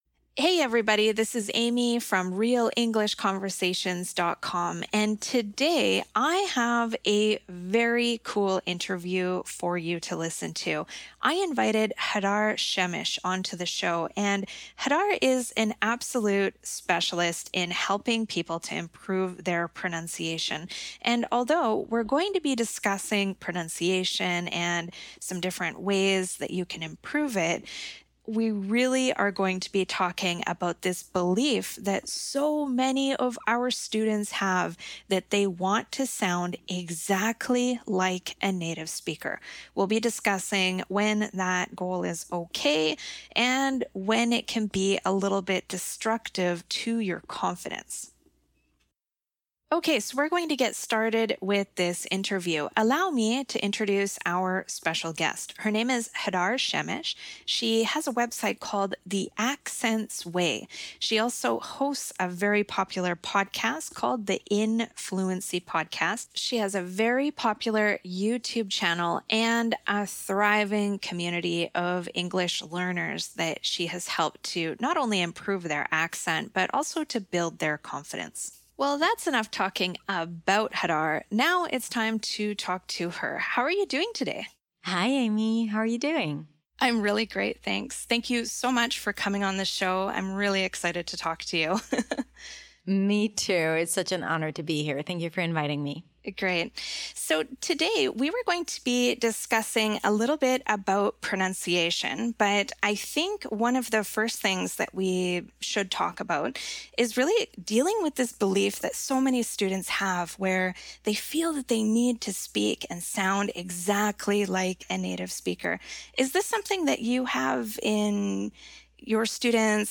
其后的某一天我随机刷到了一条关于英语发音的播客音频，主要讲口音和发音的。